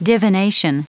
Prononciation du mot divination en anglais (fichier audio)
Prononciation du mot : divination